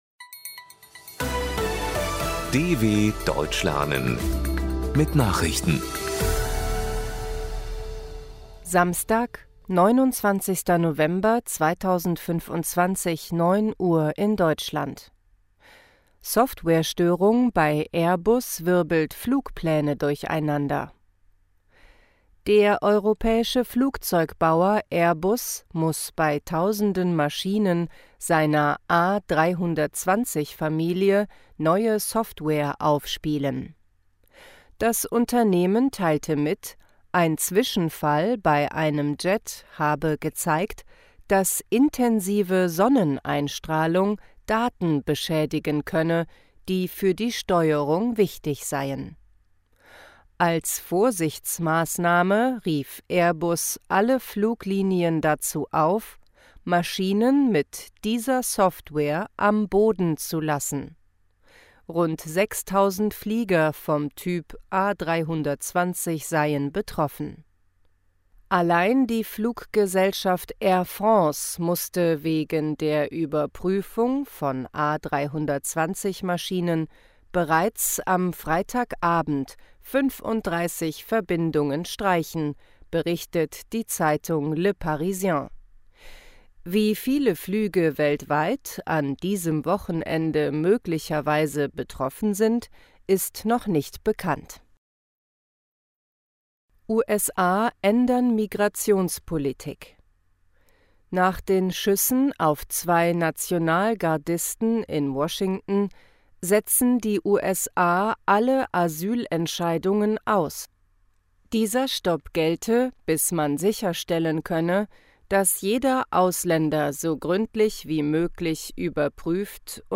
29.11.2025 – Langsam Gesprochene Nachrichten
Trainiere dein Hörverstehen mit den Nachrichten der DW von Samstag – als Text und als verständlich gesprochene Audio-Datei.